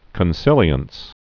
(kən-sĭlē-əns)